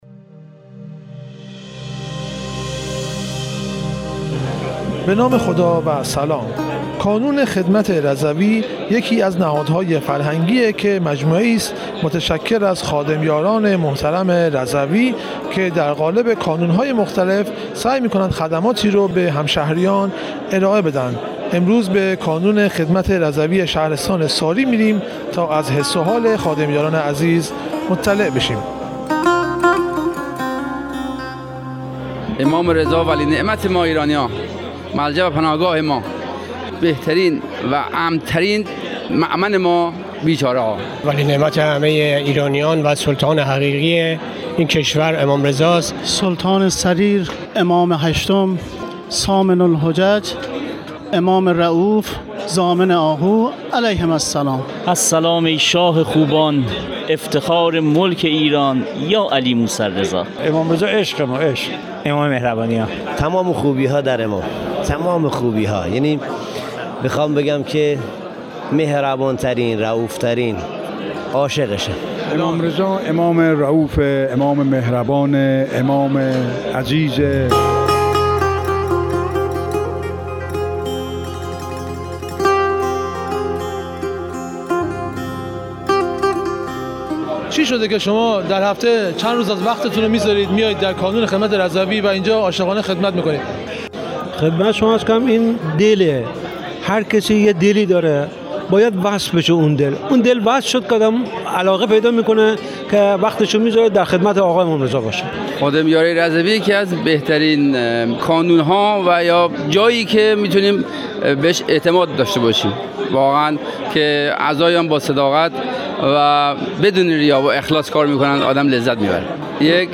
در رادیو رضوی بشنوید